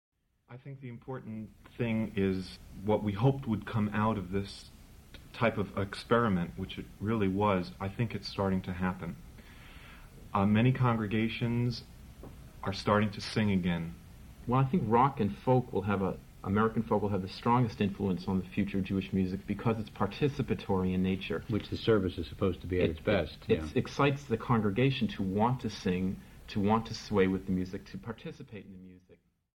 Radio Interview 5